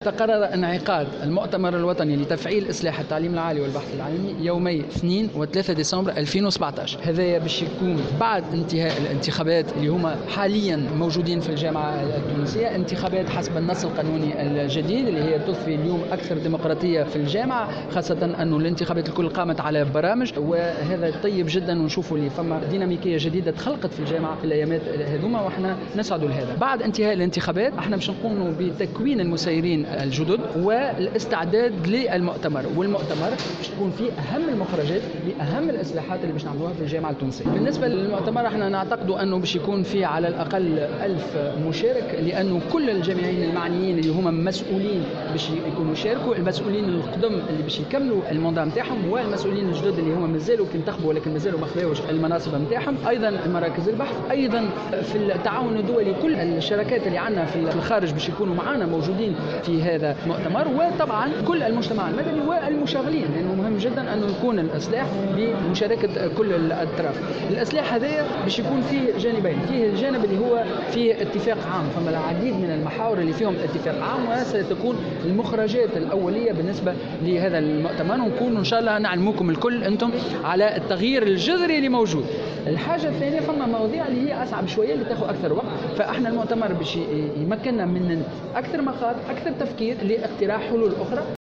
أكد وزير التعليم العالي والبحث العلمي، سليم خلبوس أن المؤتمر الوطني لتفعيل إصلاح التعليم العالي والبحث العلمي، سينعقد يومي 2 و3 ديسمبر المقبل، أي بعد انتخابات الهياكل الجامعية. وأضاف في تصريح لمراسلة "الجوهرة أف أم" على هامش ندوة صحفية عقدتها الوزارة اليوم الجمعة، أنه سيتم خلال هذا المؤتمر الذي سيحضره حوالي ألف مشارك، تناول أهم مخرجات إصلاح منظومة التعليم العالي.